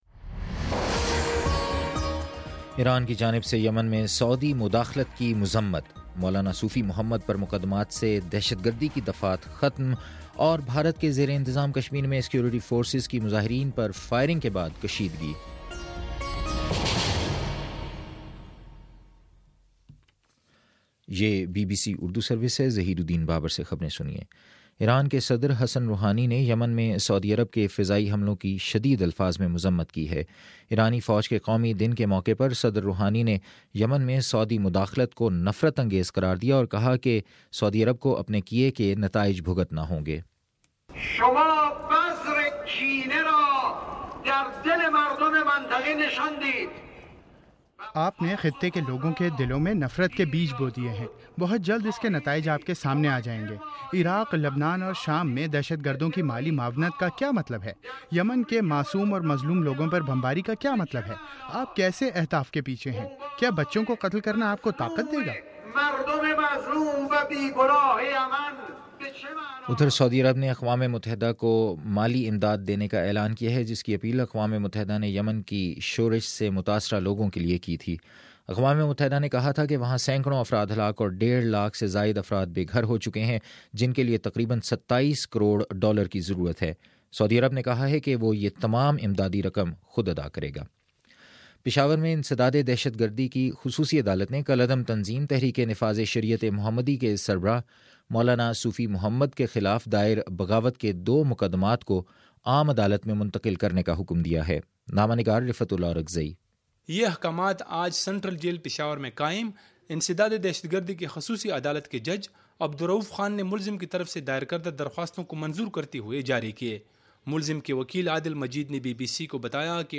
اپریل 18 : شام سات بجے کا نیوز بُلیٹن